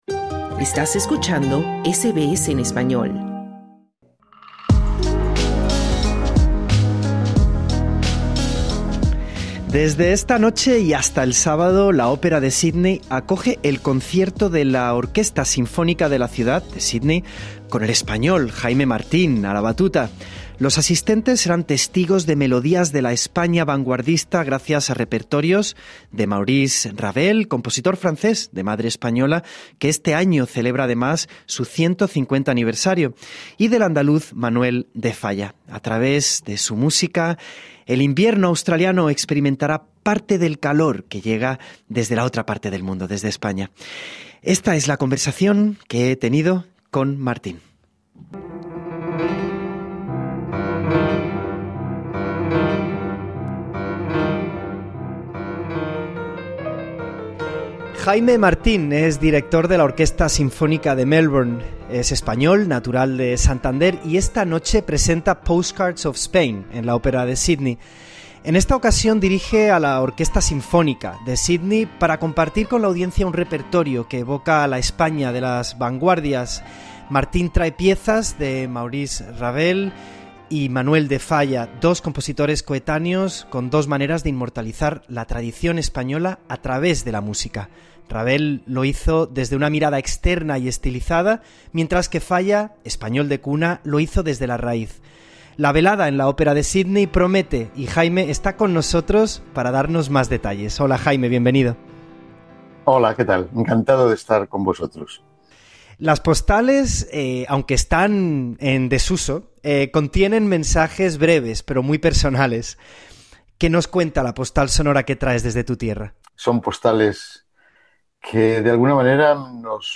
Martín nos ayuda a entender por qué Ravel y Falla fueron dos figuras trascendentales en la música y cómo se inspiraron mutuamente. Sinopsis Jaime Martín nos atiende desde su hotel en Sídney.